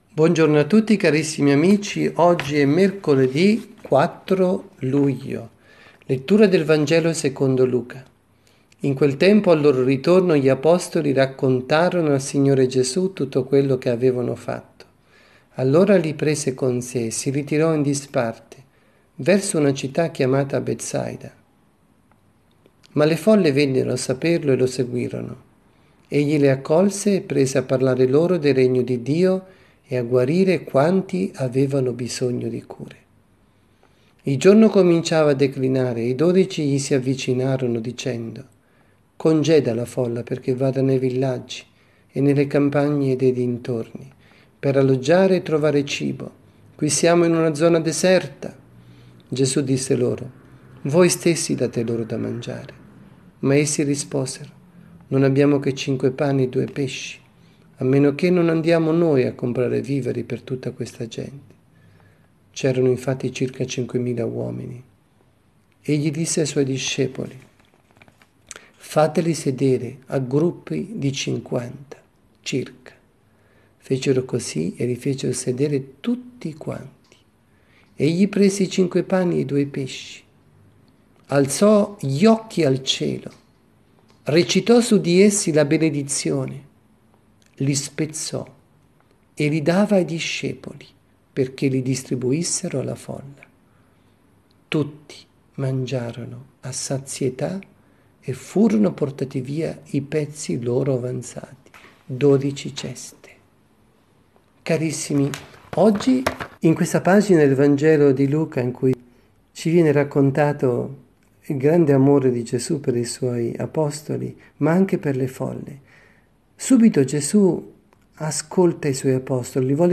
avvisi, Omelie